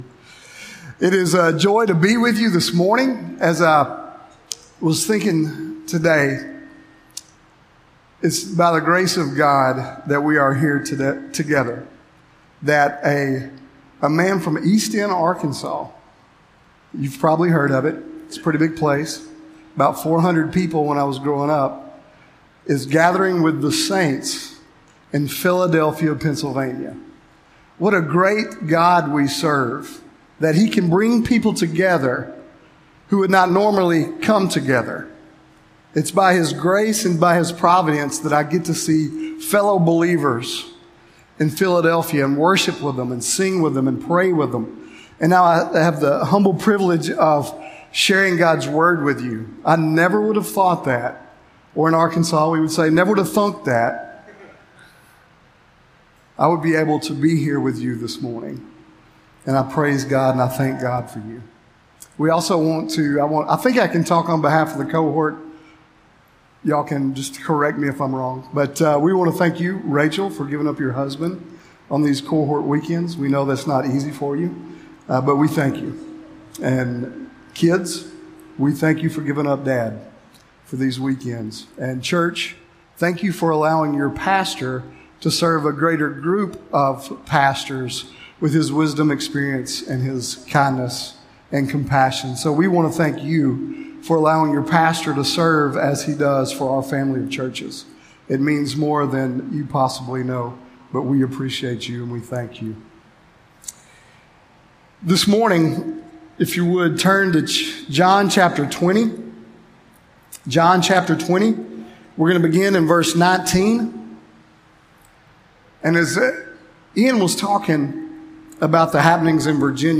A sermon from the series "Stand Alone Sermons."